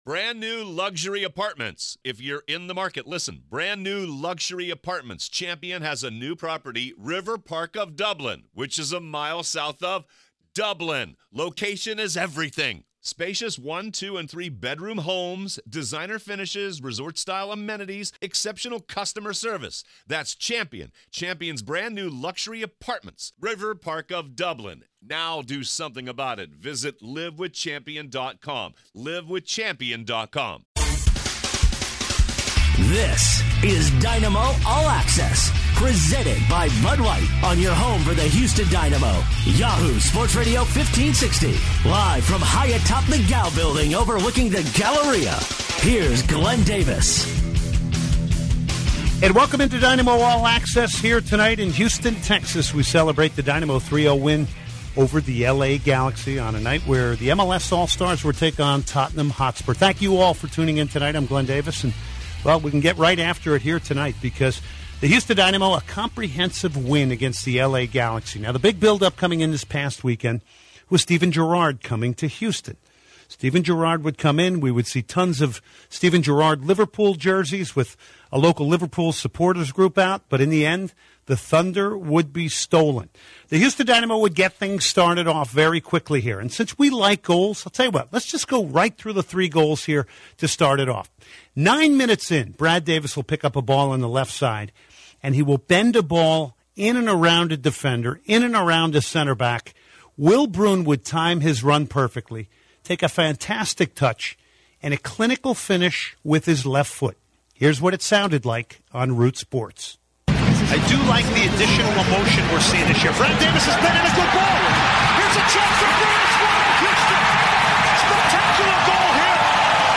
He also discusses Cubo Torres skills and what he brings to the Dynamo, and features an interview with him about his transition to Houston.
He also interviews Ownen Coyle, manager of the Dynamo to discuss the win over the Galaxy and player rotation.